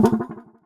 [Perc] Tin.wav